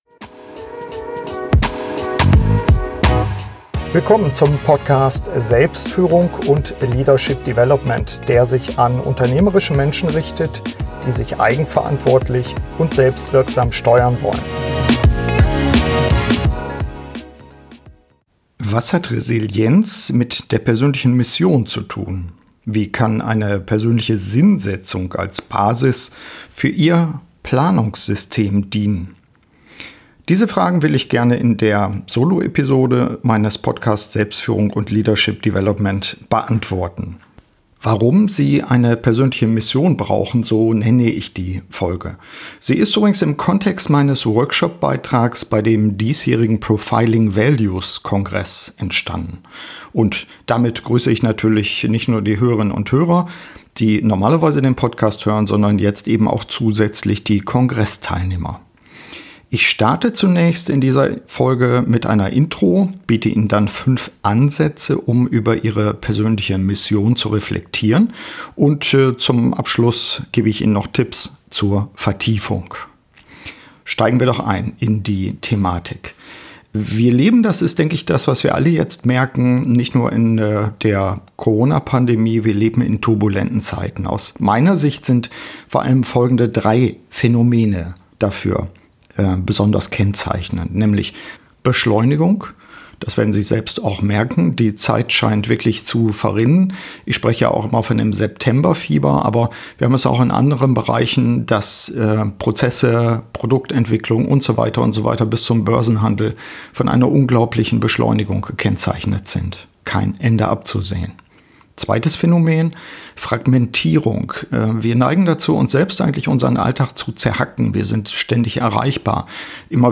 Diese und weitere Fragen beantworte ich in dieser Soloepisode meines Podcasts.